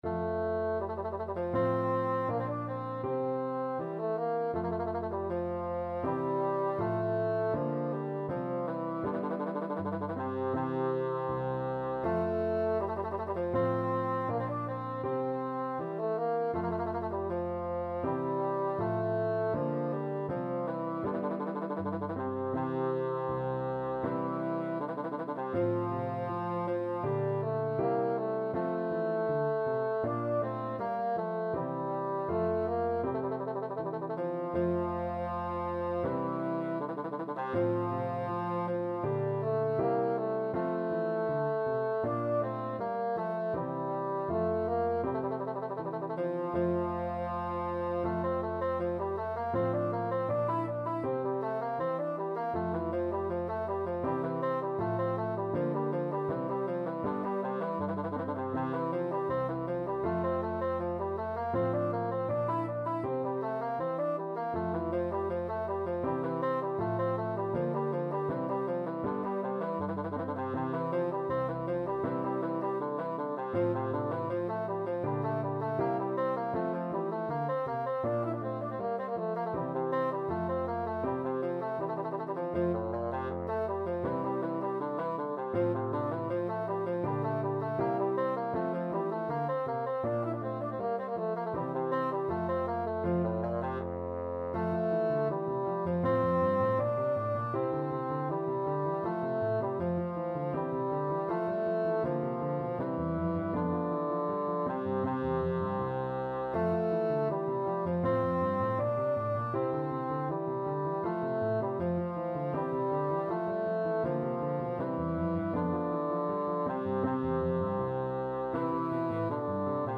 Classical Pachelbel, Johann Aria Bassoon version
Bassoon
F major (Sounding Pitch) (View more F major Music for Bassoon )
Allegretto =80
Classical (View more Classical Bassoon Music)